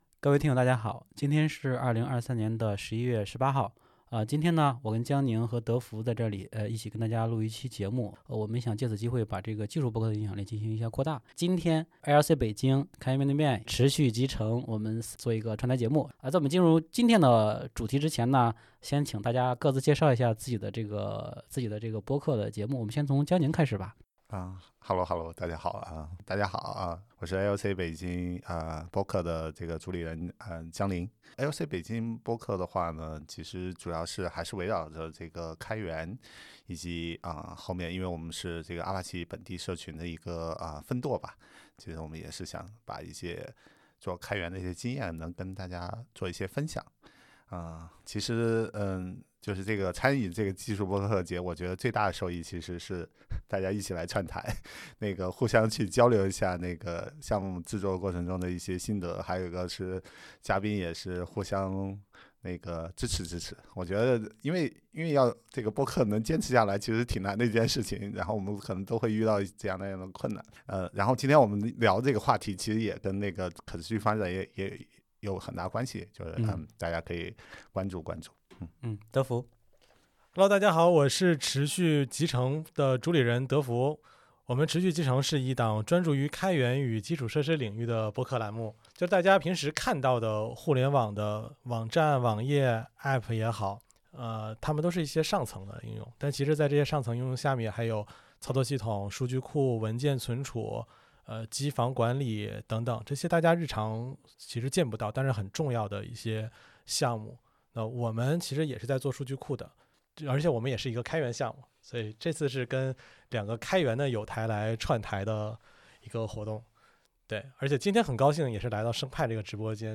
那么开源中的 Burn out 问题到底是怎么回事？开源面对面、 ALC Beijing 和持续集成三家播客在本期的串台节目中详细聊了聊相关的内容，包括但不限于：